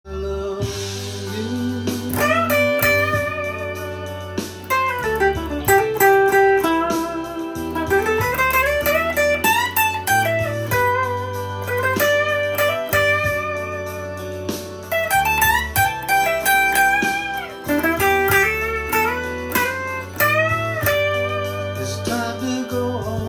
バラード調の曲でいつもライブで演奏する
譜面通り弾いてみました
ギター特有の装飾音符から始まります。
右手でミュートしながら低音弦を巻き込むようにピッキングし
左手でチョーキングします。